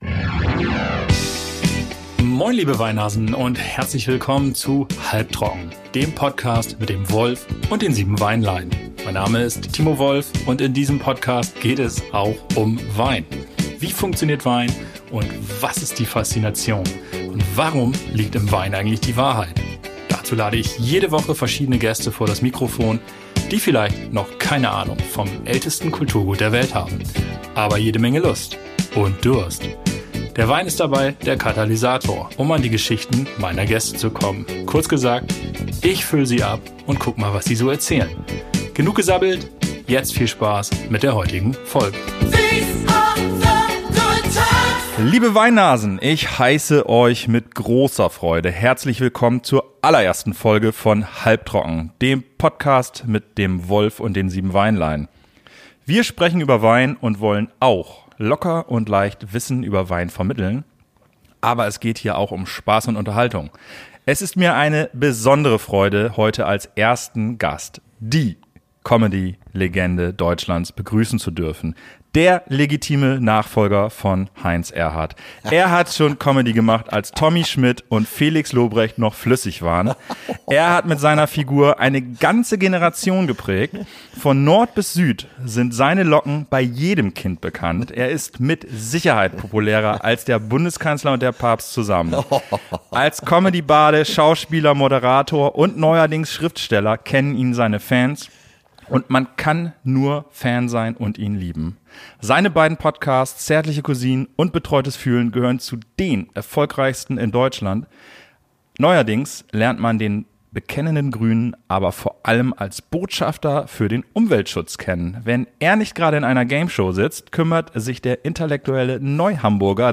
In der glühenden Hitze Mallorcas haben wir drei Spanier für Euch vernascht. Alles Spannende über seine Biografie Blauäugig – mein Leben als Atze Schröder, Gedanken zur Nachhaltigkeit und fabelhafte Trinksprüche und Anekdoten in Folge Numero uno.